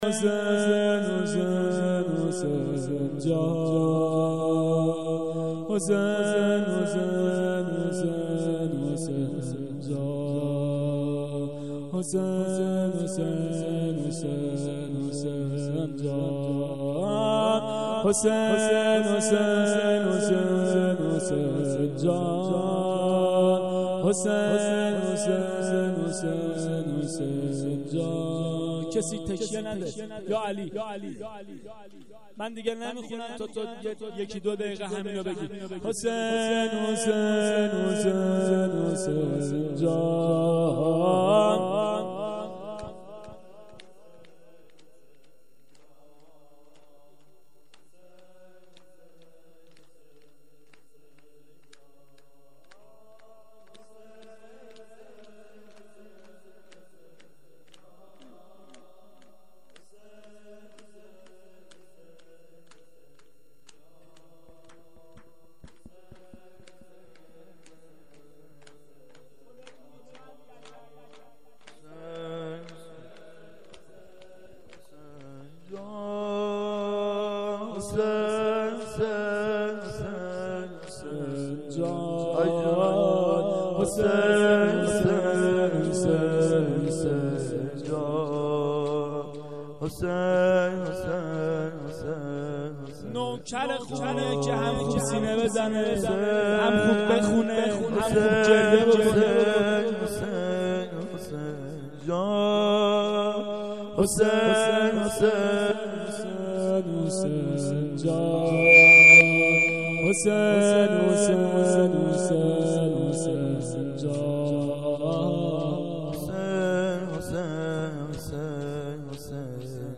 زمزمه
هفتگی 92/07/16 هیات العباس